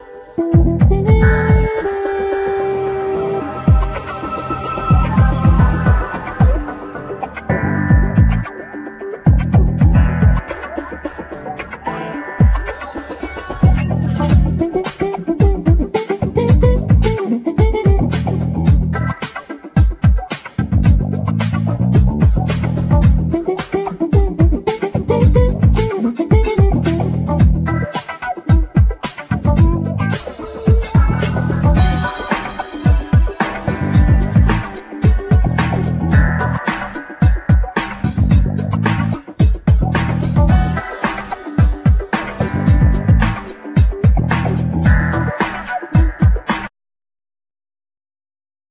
Trumpet,Flugelhorn
Bass,Keyboards,Guitar,Samples
Hammond organ,Rhodes piano
Drums
Guitar,Pedal steel,Violin